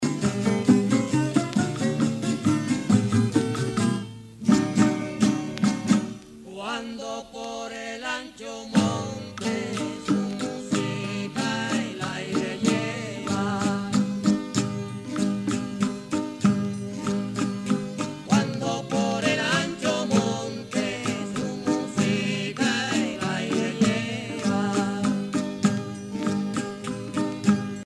Punto corrido
Sancti Spiritus, Cuba
Pièce musicale inédite